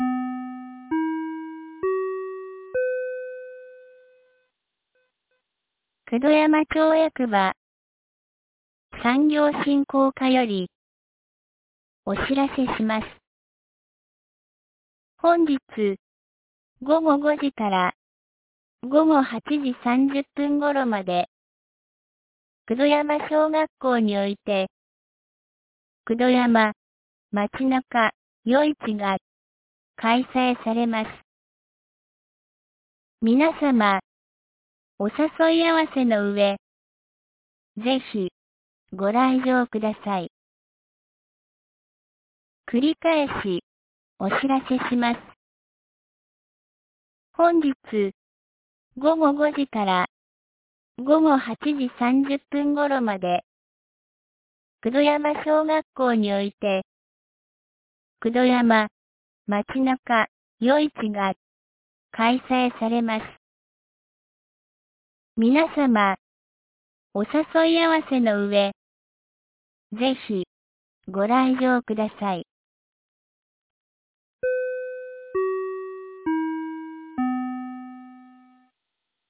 2025年07月20日 10時06分に、九度山町より全地区へ放送がありました。
放送音声